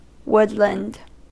/ˈwʊd.lənd/
woodland-us.mp3